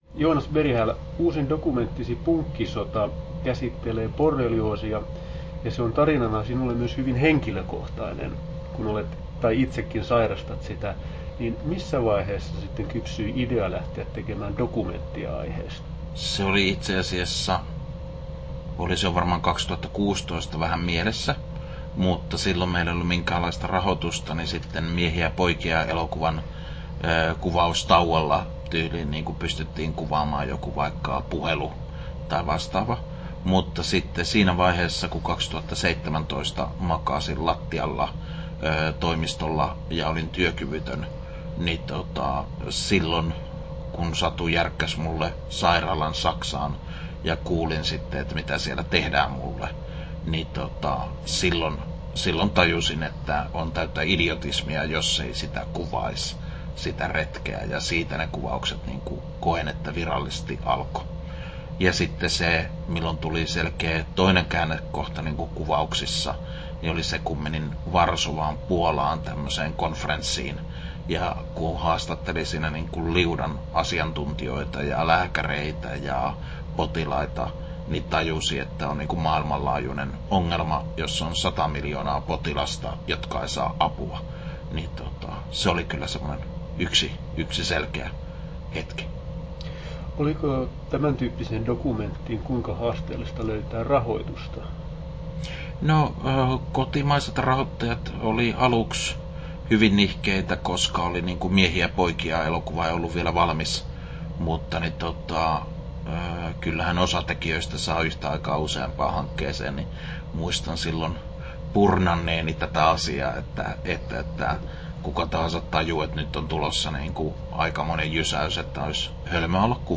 Haastattelussa Joonas Berghäll Kesto: 11'25" Tallennettu: 11.5.2021, Turku Toimittaja